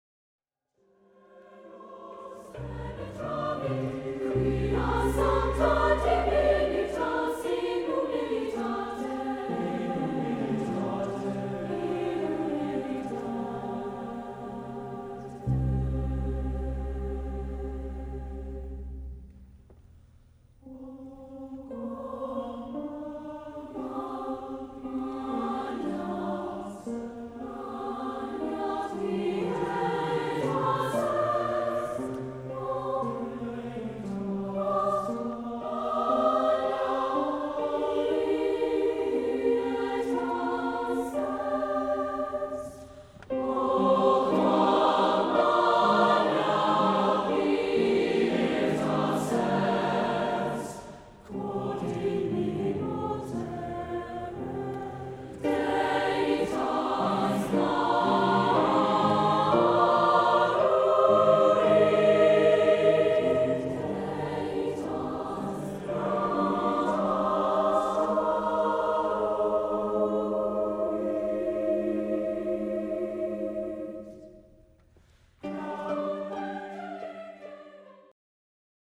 SATB, amplified bass, dumbeck